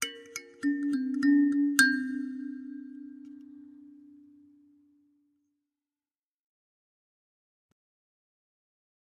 Kalimba, Accent, Type 1